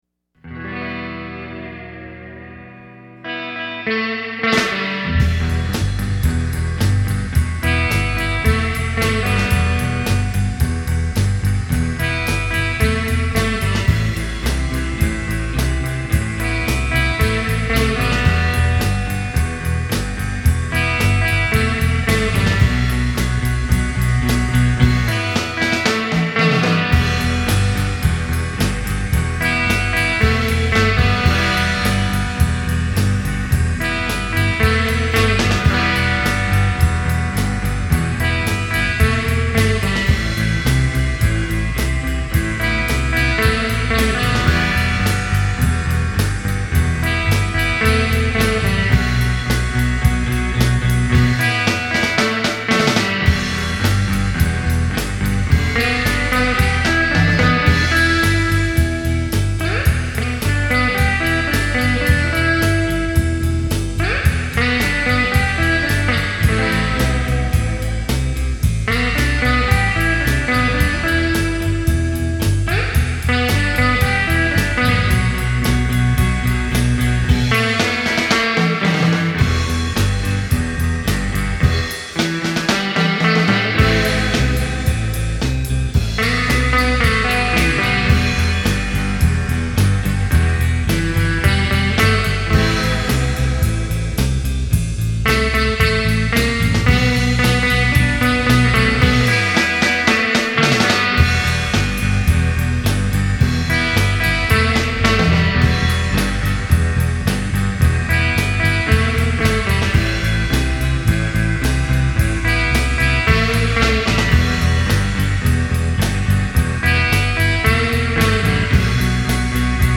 Instrumental Surf Rock
Yeah, it does have a kinda trippy feel.